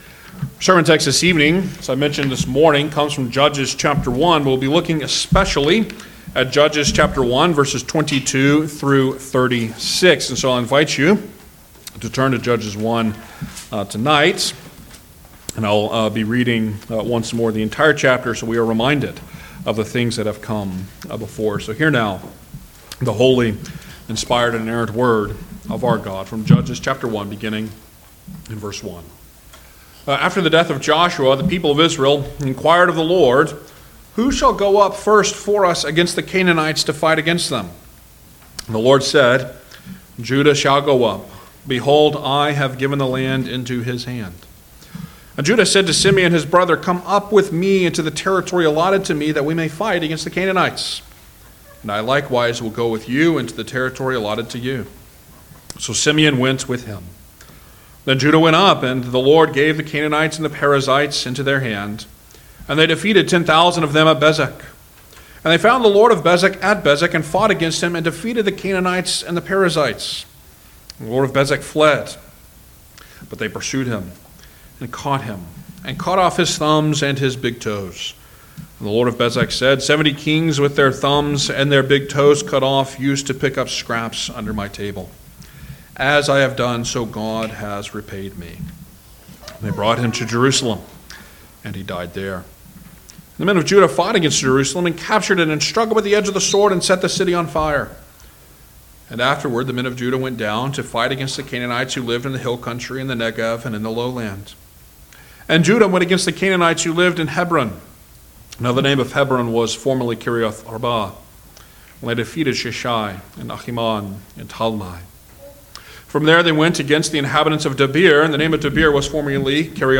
Guest Preachers Passage: Judges 1:22-36 Service Type: Sunday Evening Service Download the order of worship here .